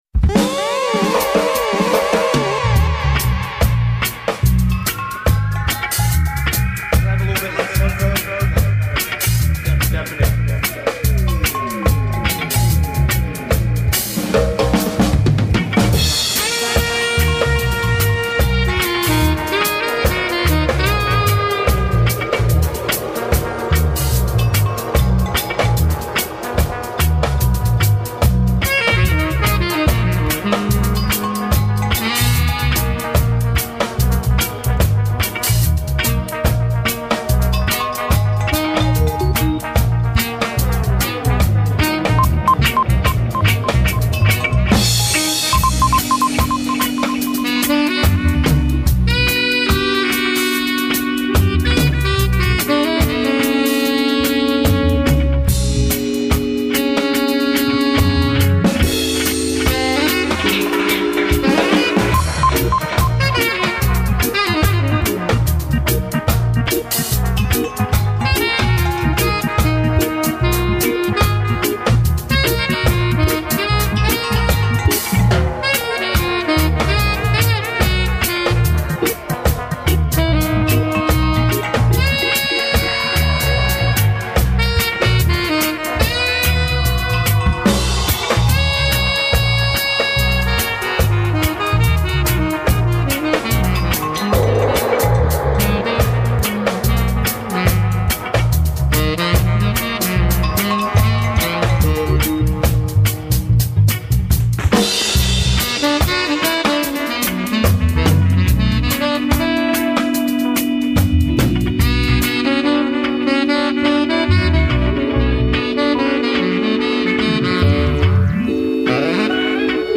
HEAVYWEIGHT DUB BIZNESS